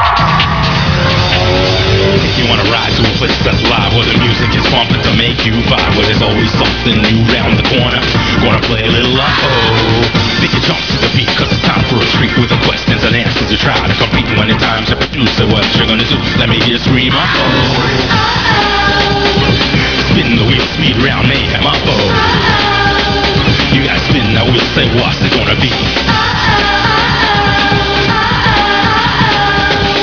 The catchy theme music (lyrics too!)